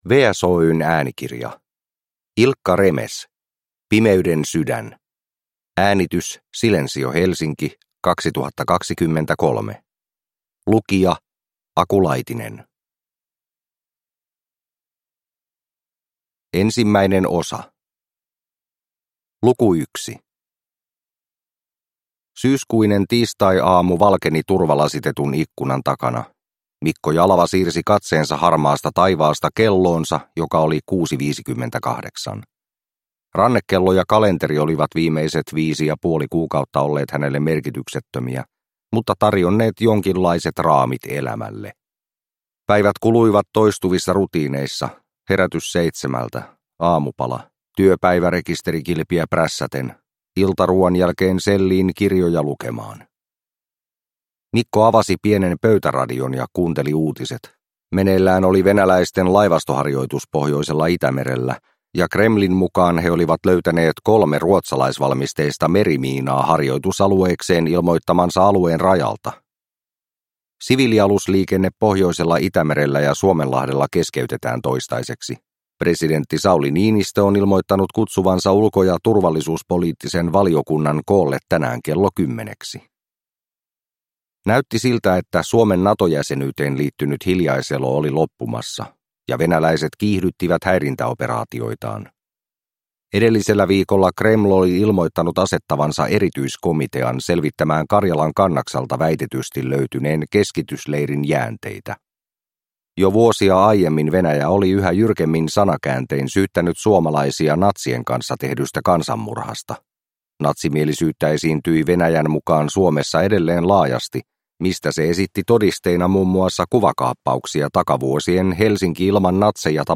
Pimeyden sydän – Ljudbok – Laddas ner